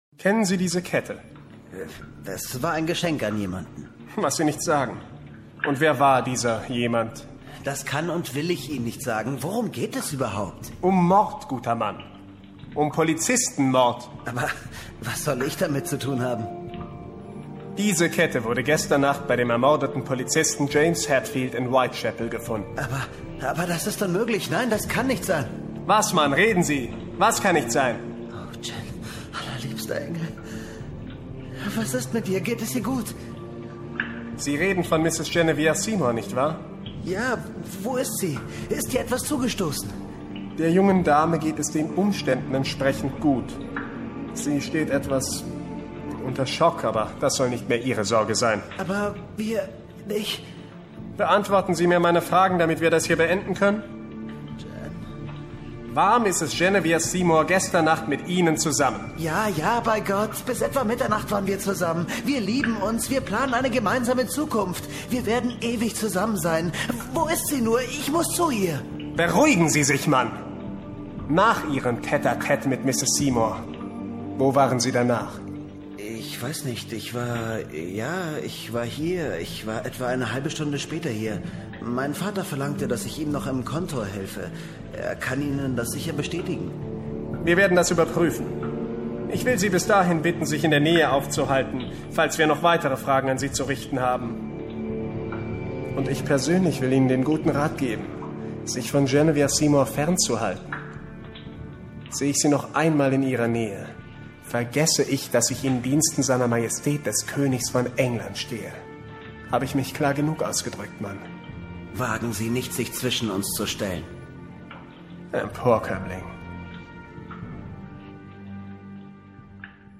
Sprechprobe: Industrie (Muttersprache):
german voice over talent, computer-gemes, audiobooks ...